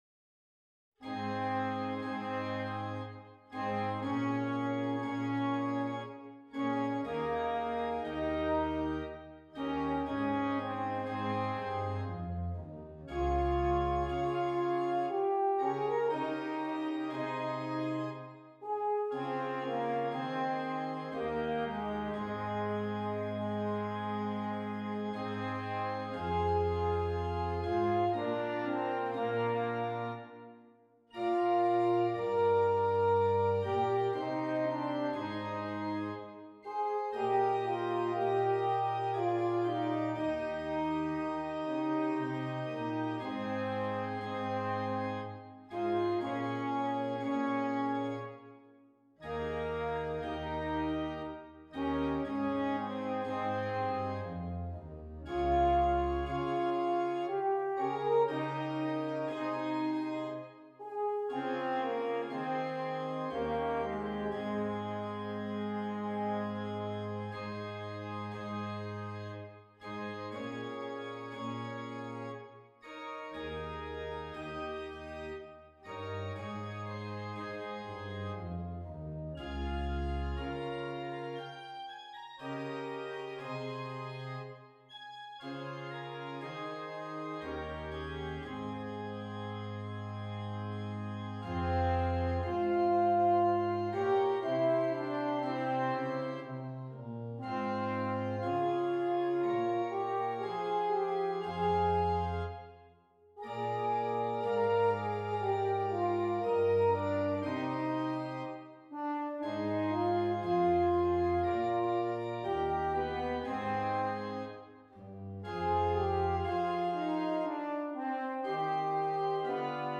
F Horn and Keyboard